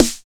23 909 SNARE.wav